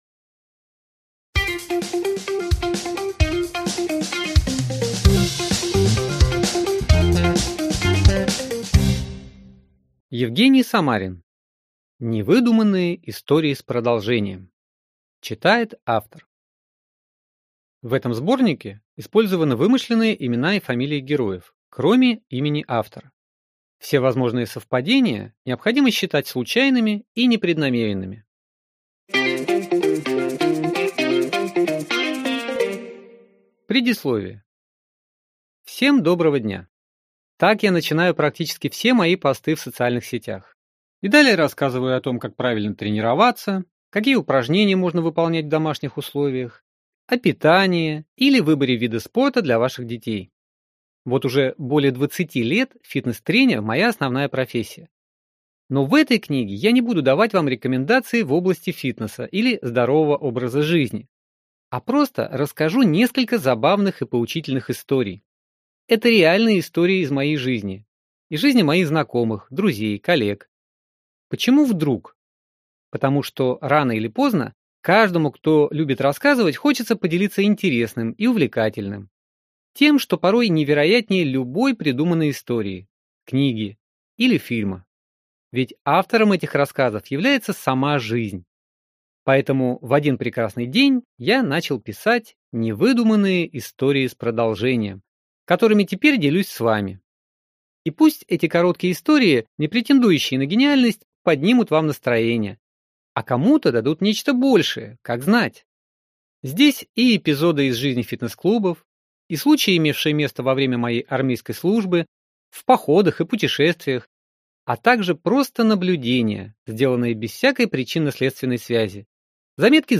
Аудиокнига Невыдуманные истории с продолжением. Все, что происходит в фитнесе ― остается в фитнесе!